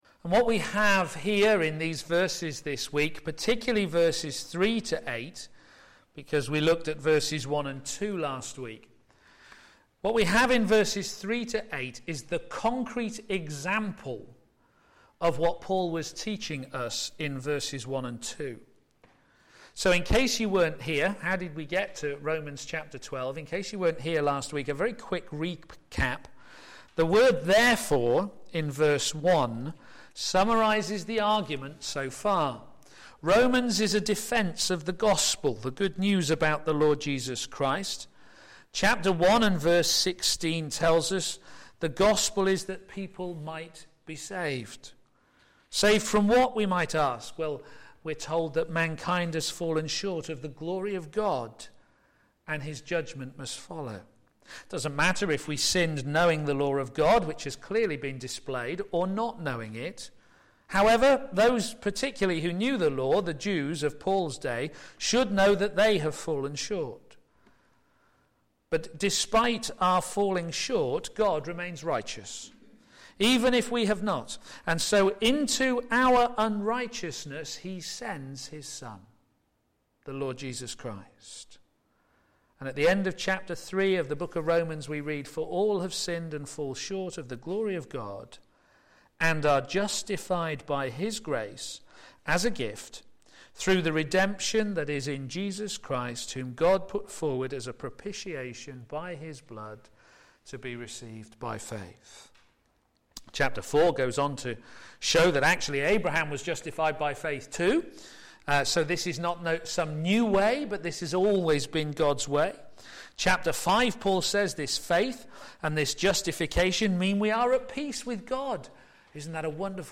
p.m. Service
Sermon